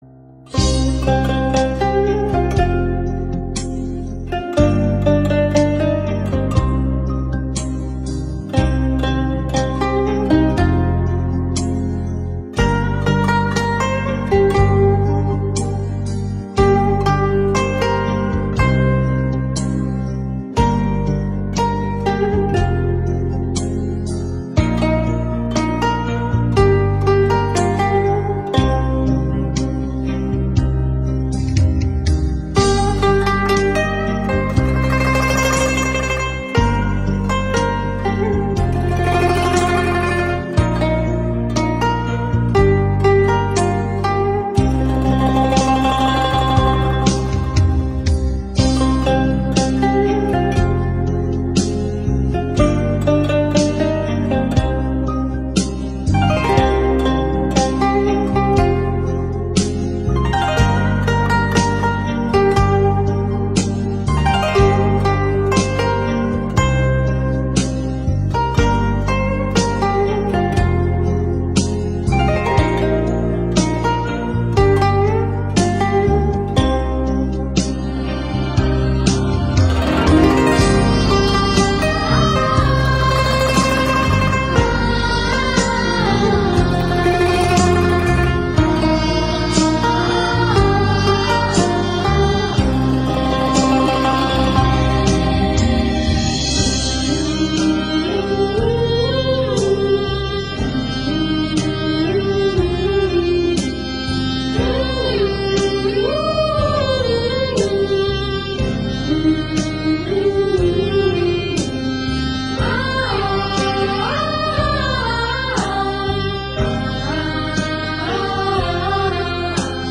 da diết, sâu lắng
bản nhạc không lời xúc động